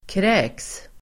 Uttal: [krä:ks]